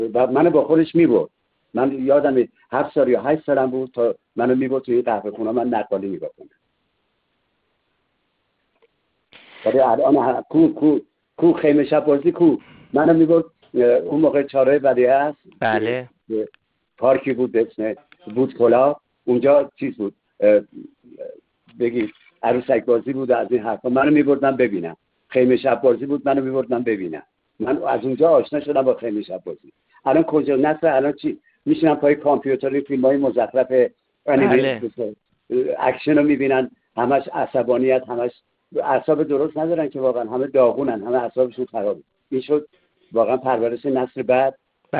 تورج نصر در گفت‌و‌گو با ایکنا: